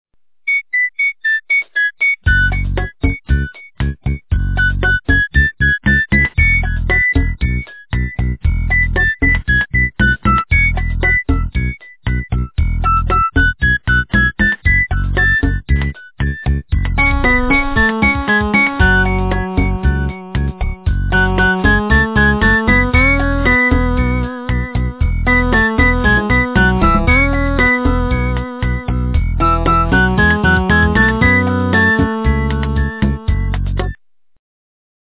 - русская эстрада
полифоническую мелодию